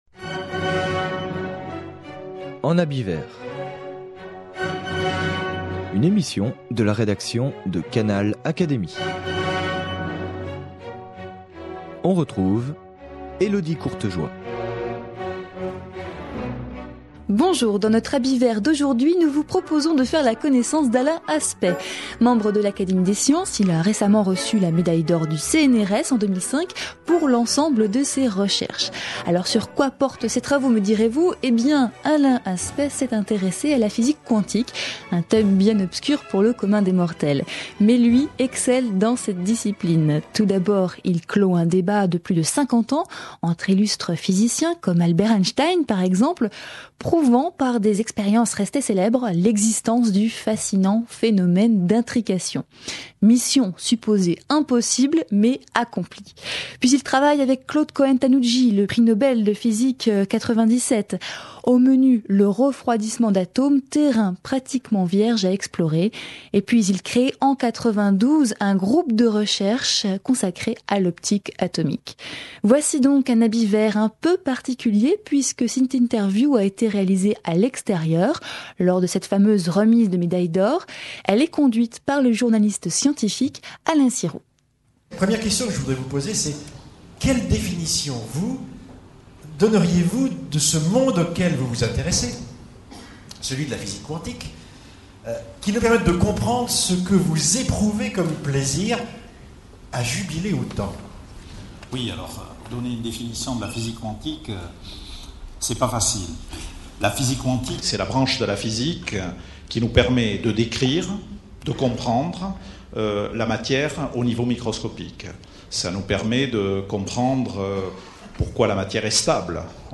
Retransmission de l’interview dans le cadre de la remise de la médaille d’or du CNRS en décembre 2005.